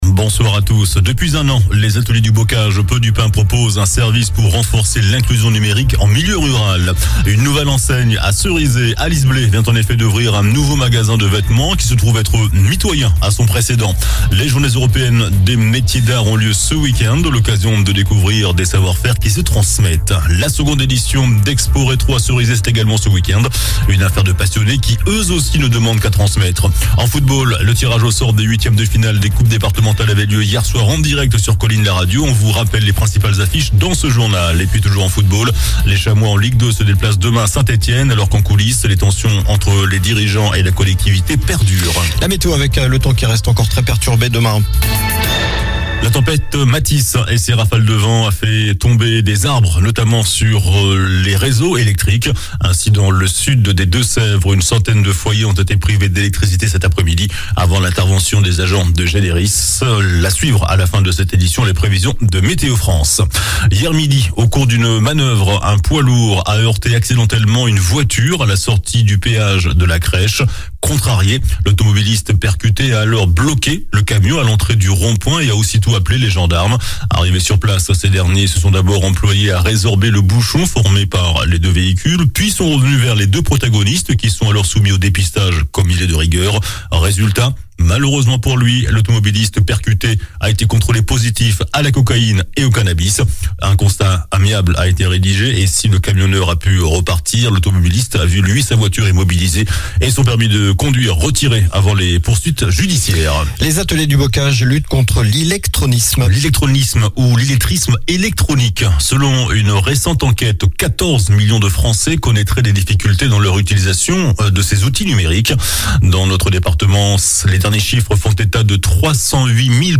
JOURNAL DU VENDREDI 31 MARS ( SOIR )